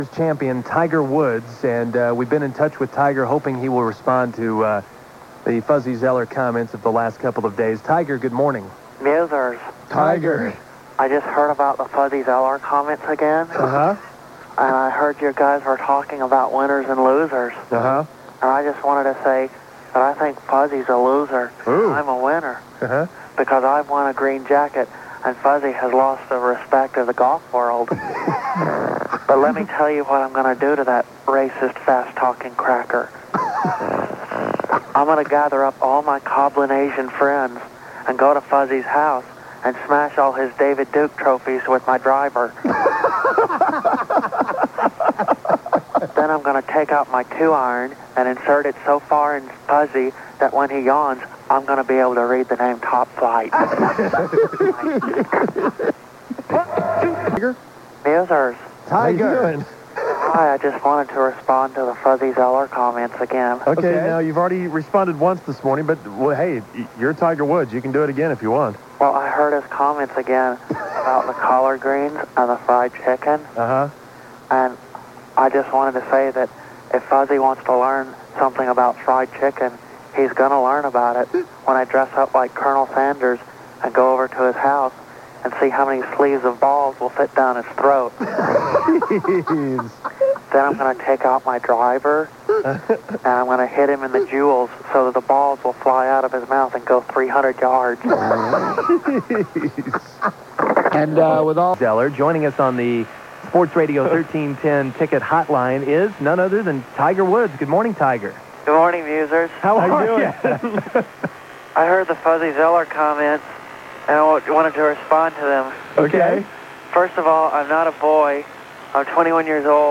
A montage of Tiger Woods reacting to Fuzzy’s comments